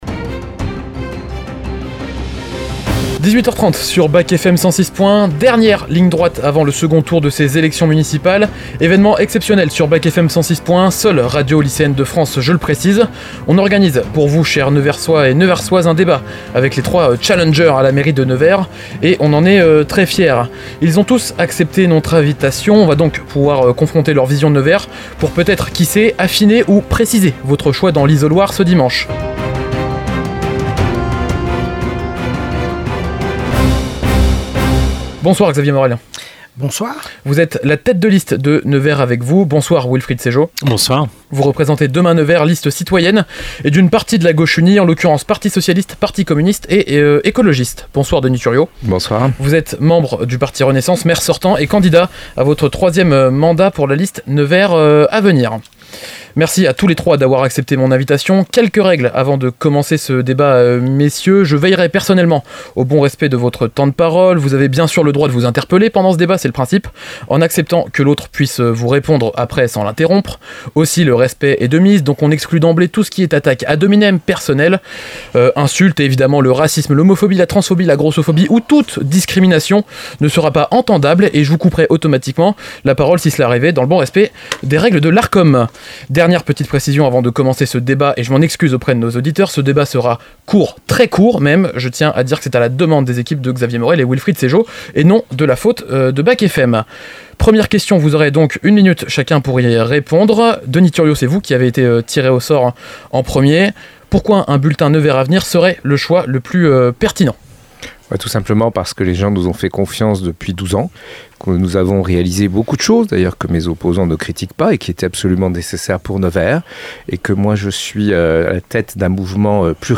Municipales, Nevers 2026 - Débat de l'entre-deux-tours en podcast - BAC FM